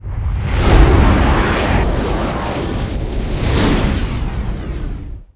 WarpEnd.wav